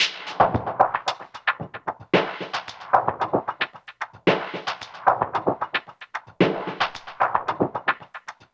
DWS SWEEP1-R.wav